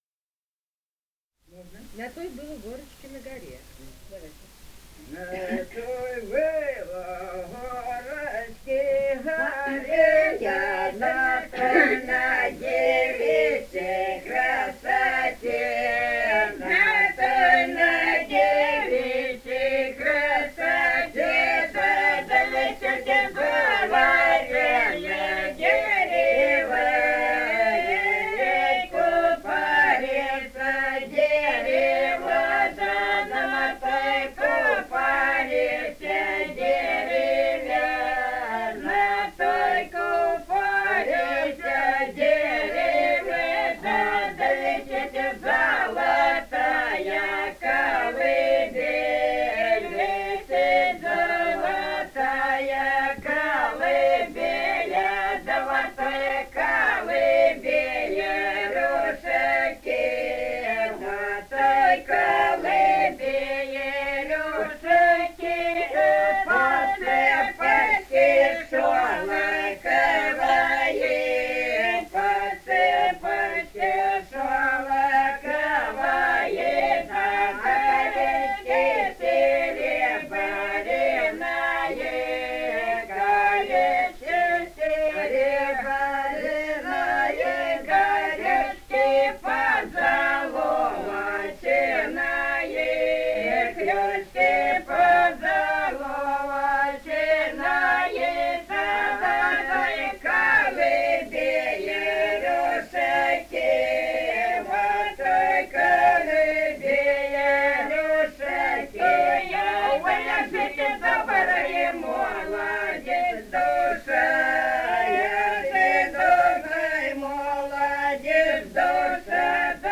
Этномузыкологические исследования и полевые материалы
Ростовская область, г. Белая Калитва, 1966 г. И0942-04